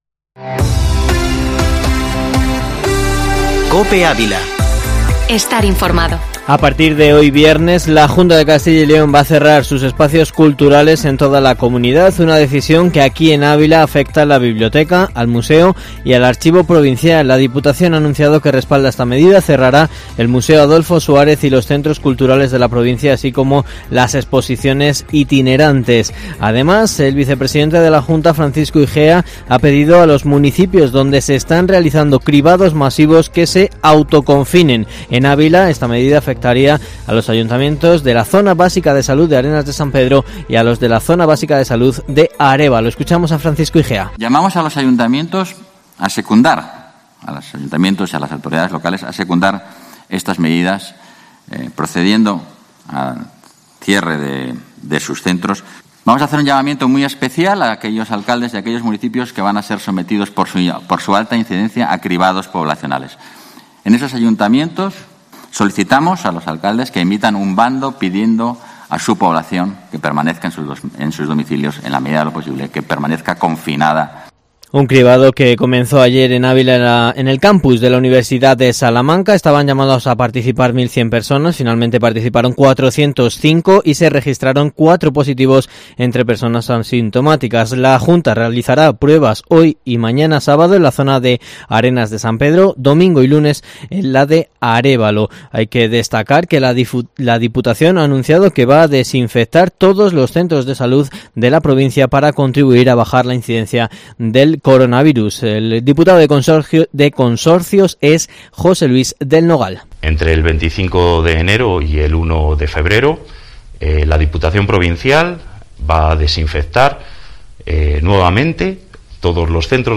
Informativo matinal Herrera en COPE Ávila 22/01/2021